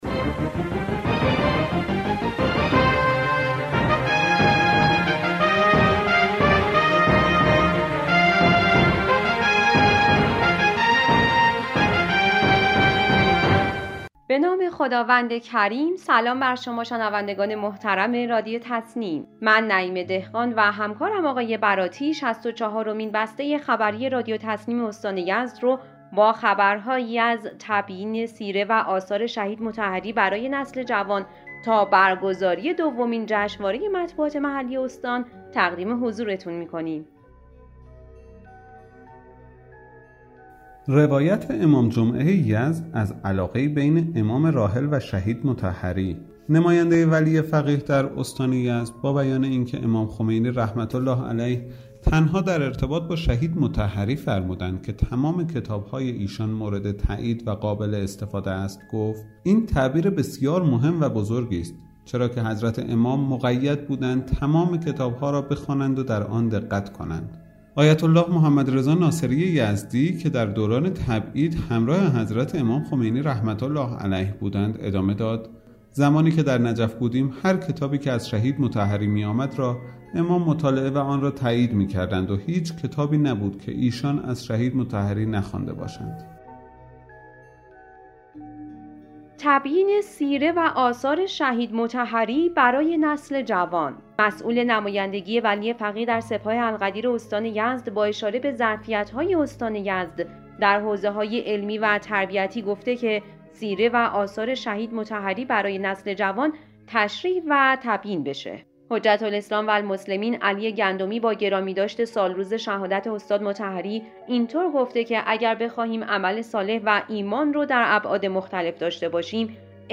بسته خبری